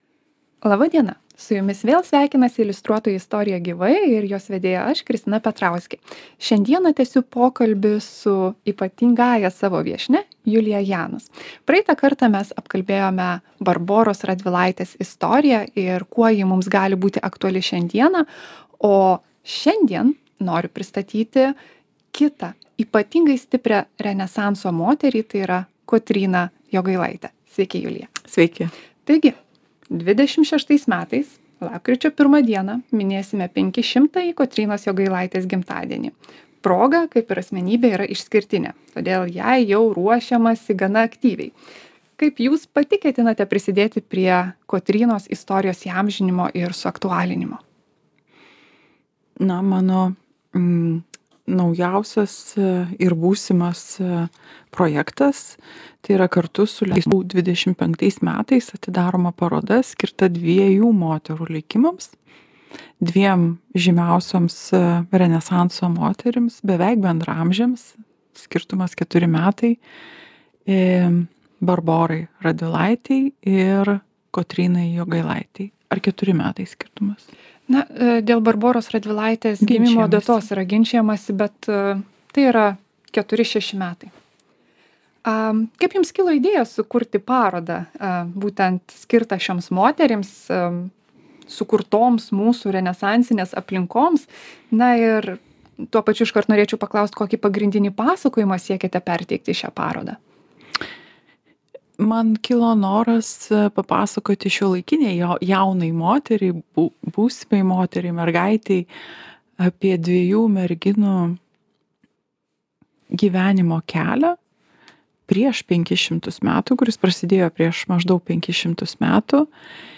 Tai – antra dalis pokalbio su tarpdisciplinine menininke